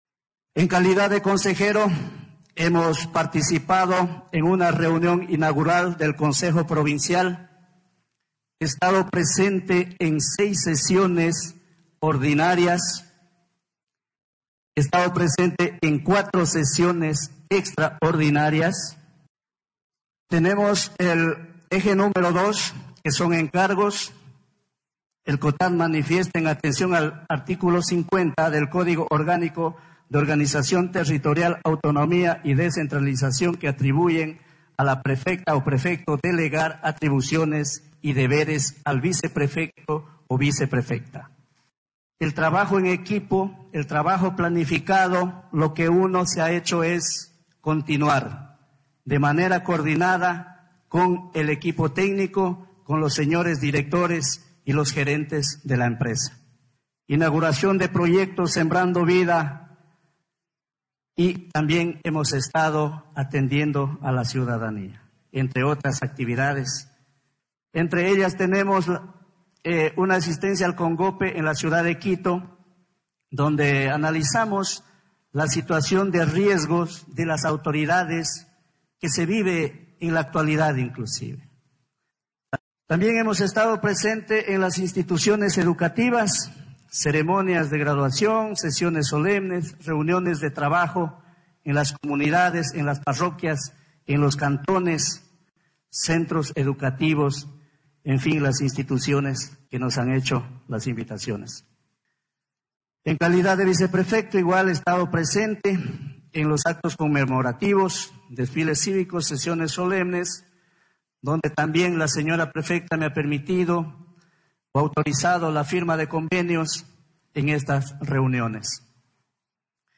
VÍCTOR SARANGO, VICEPREFECTO